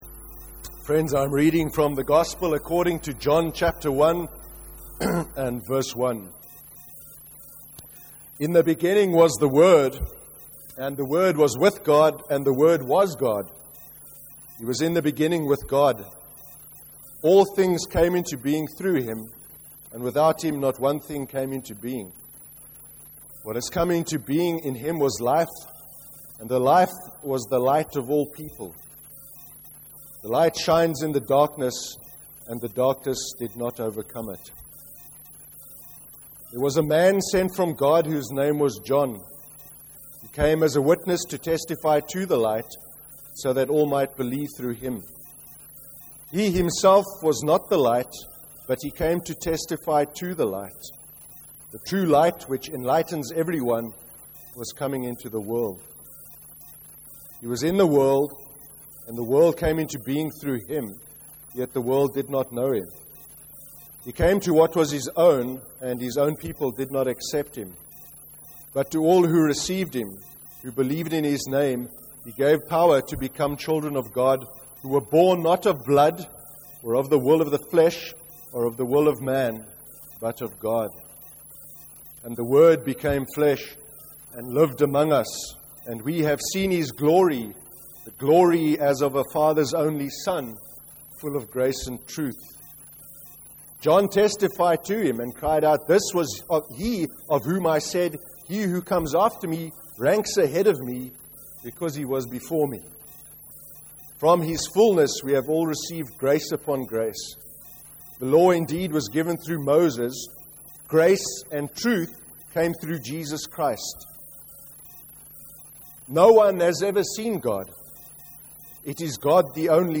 25/05/2014 sermon. The importance of our relationship with God, our Father (John 1:1-18)